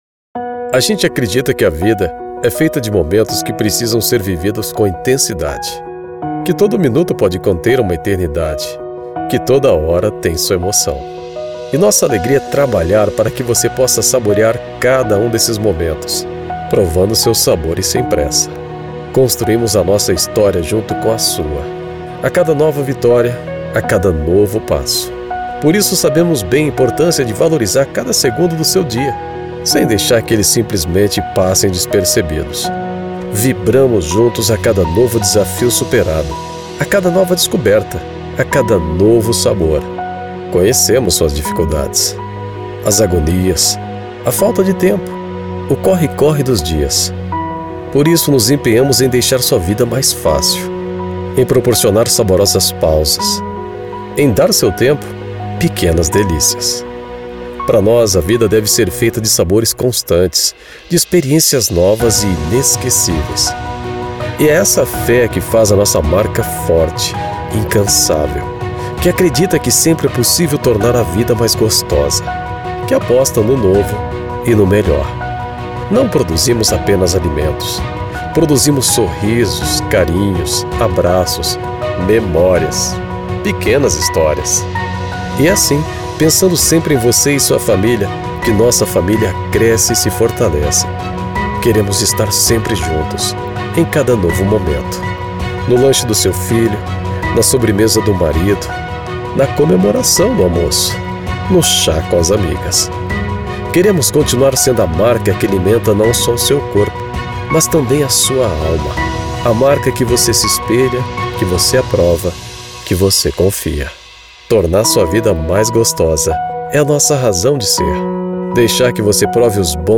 Natural, Amable, Empresarial, Comercial, Versátil
Corporativo
He presents his voice with great agility.